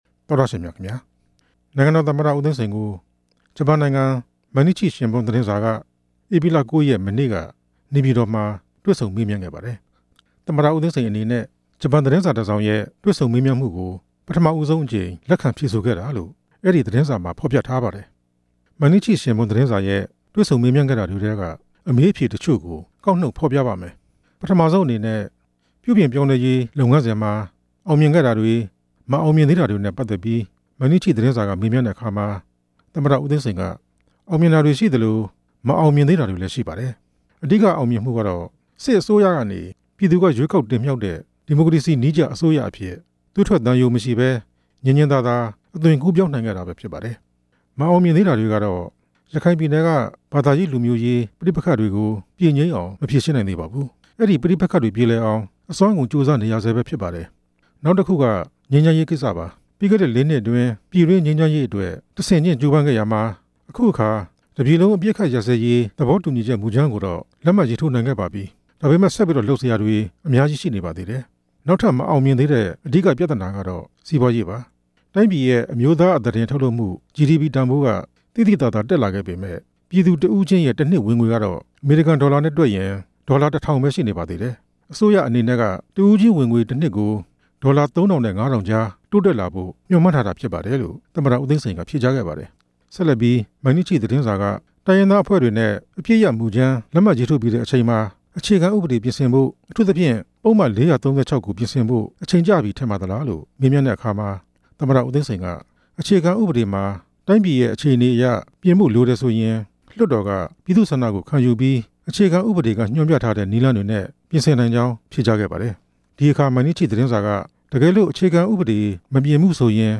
မြန်မာနိုင်ငံ အခြေအနေ သမ္မတ ဦးသိန်းစိန်နဲ့ မေးမြန်းချက်